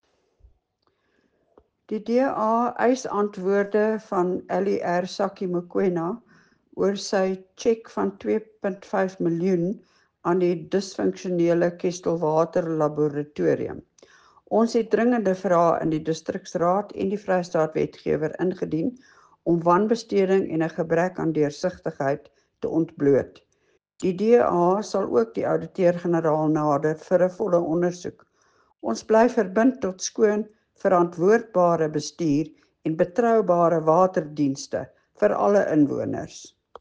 Afrikaans soundbites by Cllr Leona Kleynhans and